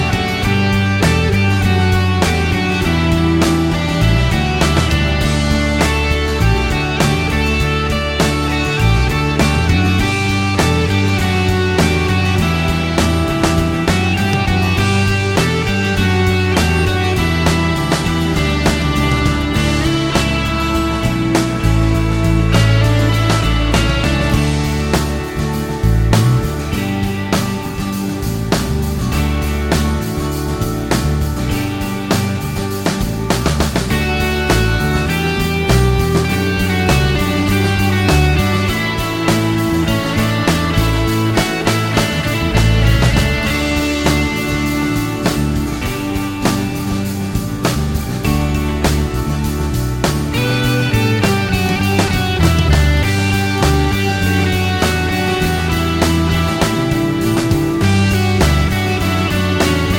no Backing Vocals Indie / Alternative 3:26 Buy £1.50